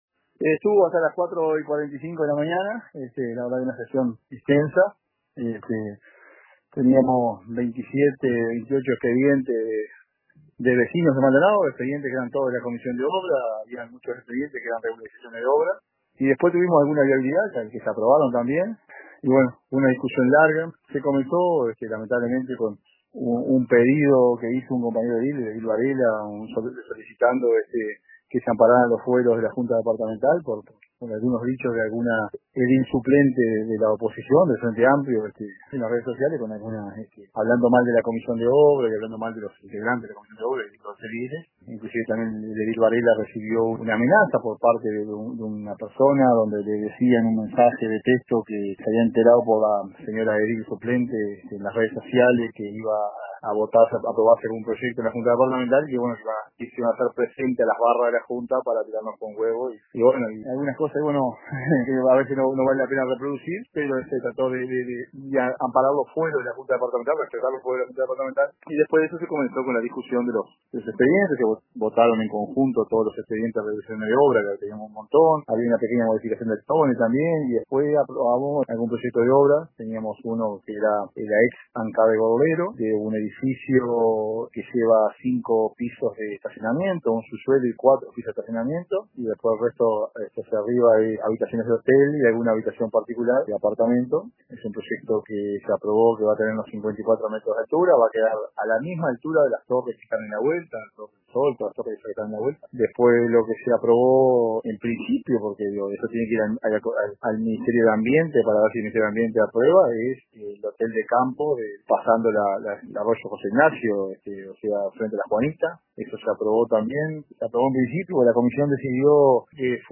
Consultado por RADIO RBC, José Luis Sánchez dijo que nosotros aprobamos algo que falta la decisión del ministerio de medio ambiente